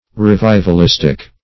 Meaning of revivalistic. revivalistic synonyms, pronunciation, spelling and more from Free Dictionary.
\Re*viv`al*is"tic\